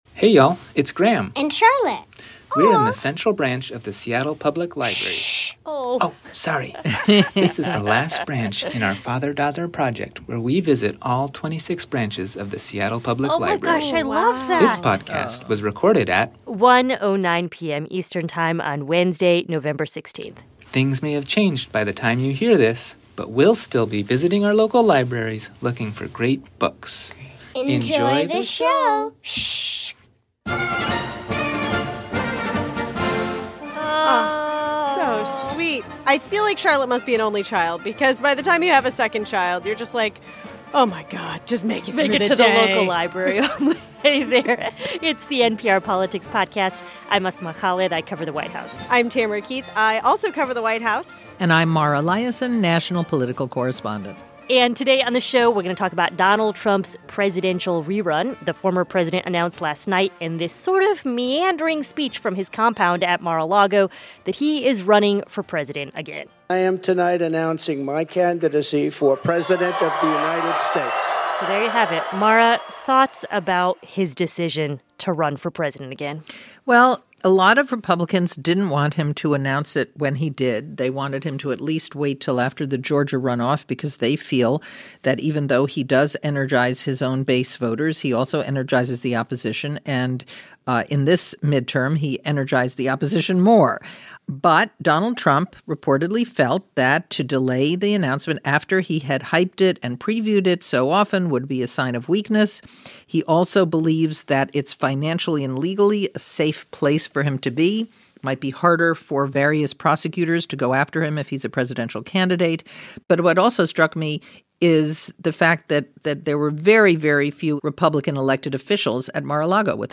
This episode: White House correspondent Asma Khalid, White House correspondent Tamara Keith, national political correspondent Mara Liasson.